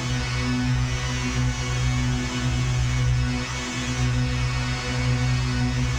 DD_LoopDrone3-B.wav